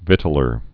(vĭtl-ər)